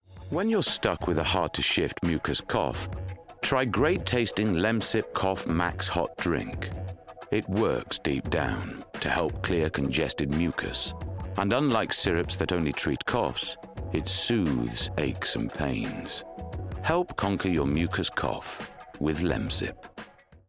Yorkshire
Male
Deep
Dry
Gravelly
LEMSIP COMMERCIAL